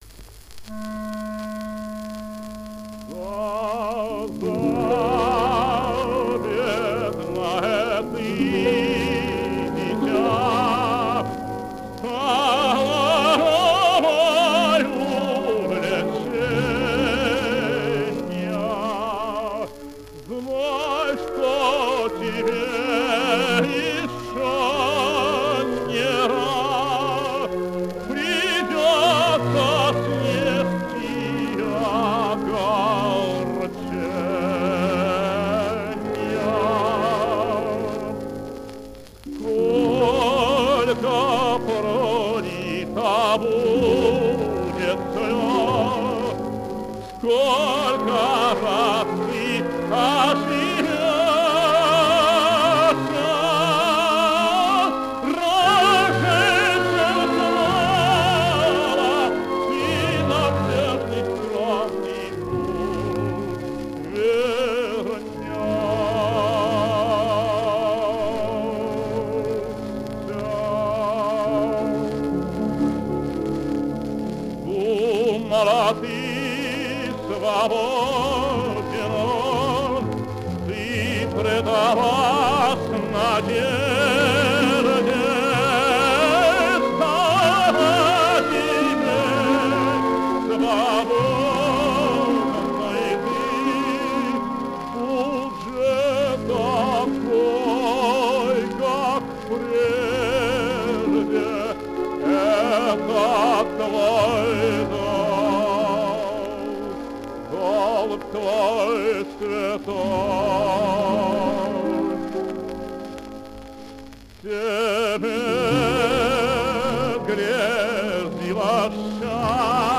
Обладал сильным, свободно льющимся голосом, драматическим темпераментом.
Руджеро Леонкавалло. Опера «Заза». Ария Каскара. Симфонический оркестр Всесоюзного Радиокомитета.
Исполняет П. В. Амиранашвили.